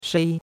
shei1.mp3